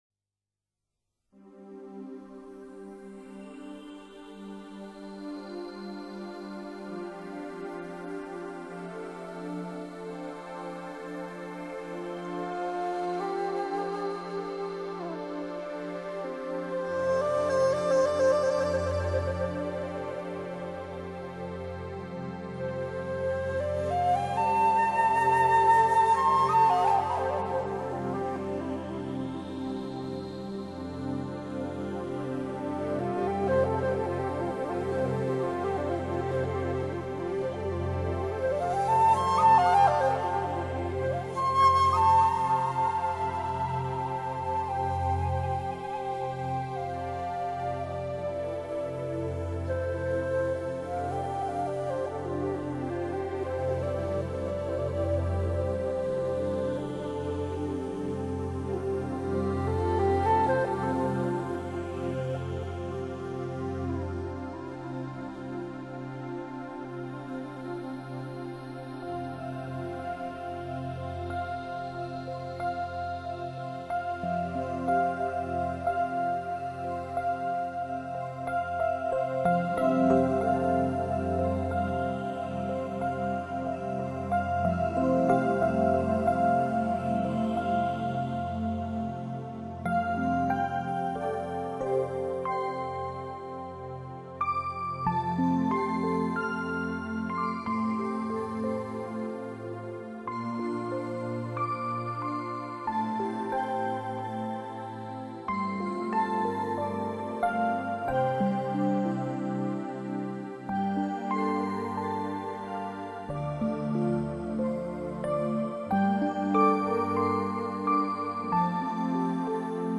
[新民乐]
朝着新世纪流行乐的方向改变这首歌，在歌中配上新的和弦，以竹笛表达平静与自在。